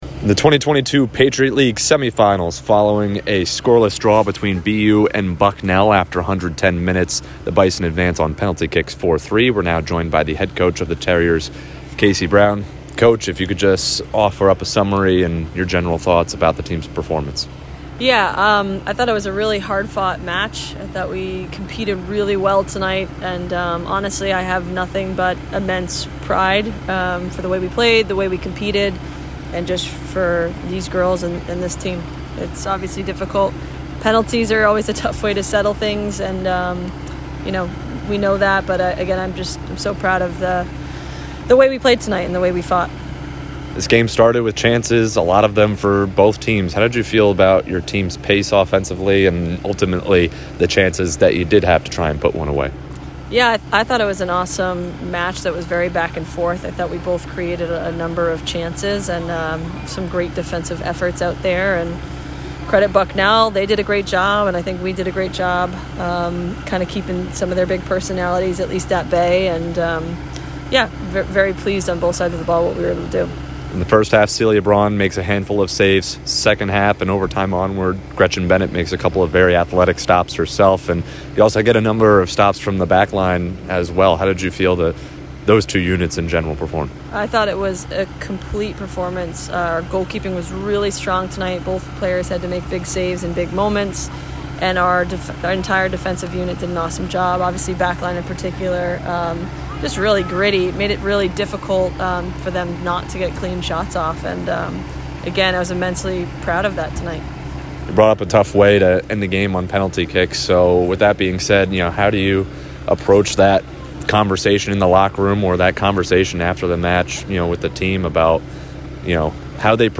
WSOC_PL_Semi_Postgame.mp3